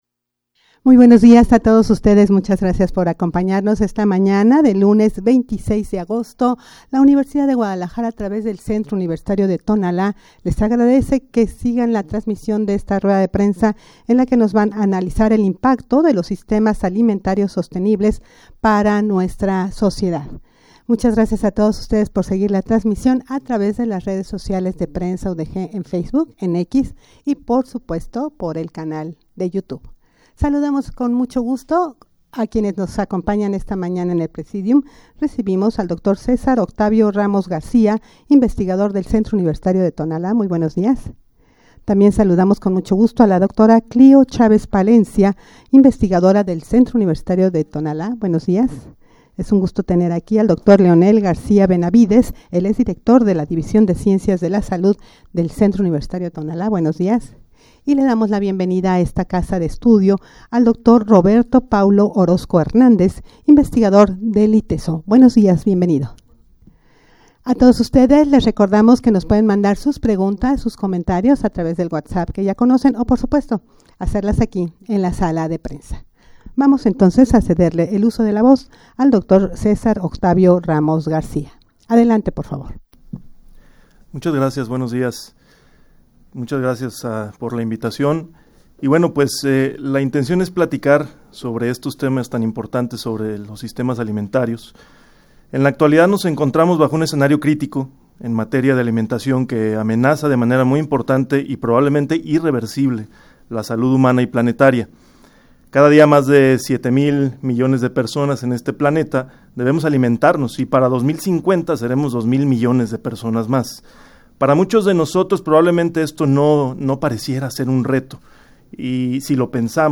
Audio de la Rueda de Prensa
rueda-de-prensa-para-analizar-el-impacto-de-los-sistemas-alimentarios-sostenibles-para-nuestra-sociedad.mp3